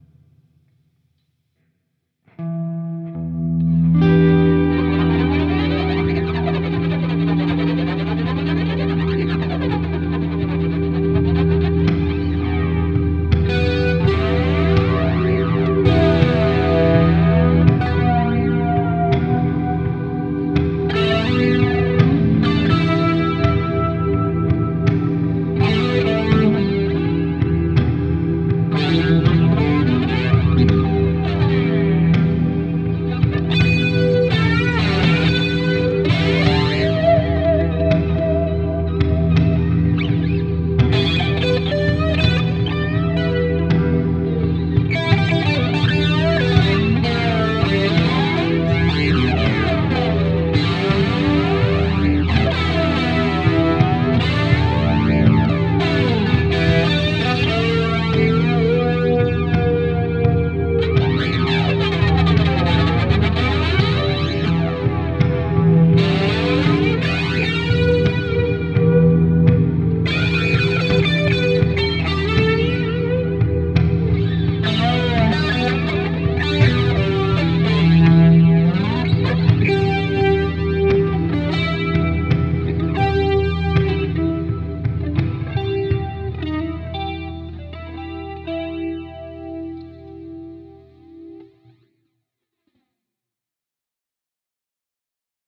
- Laadi annetun taustan päälle solistinen osuus valitsemallasi instrumentilla
Rajua rypistystä kaikuisassa ympäristössä.
Niin seitkytlukua.
mystinen tunnelma 2p